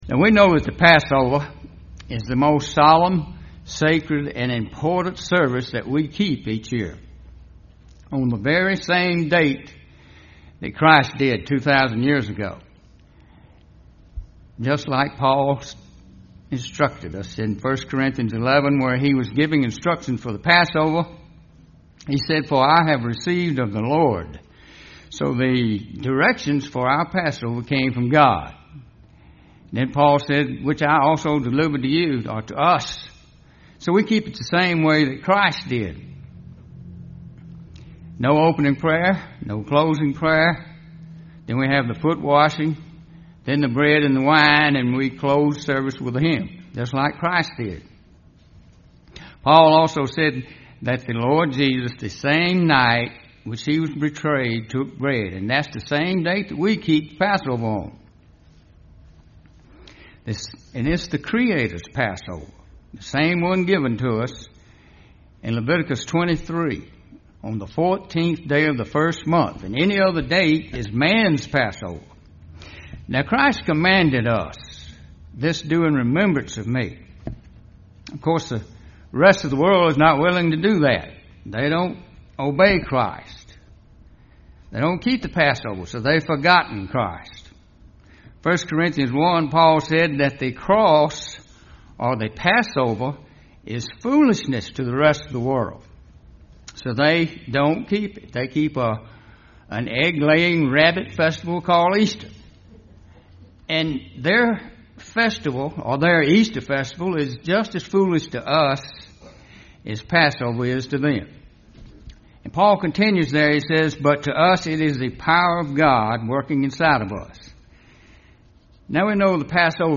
UCG Sermon Studying the bible?
Given in Columbus, GA Central Georgia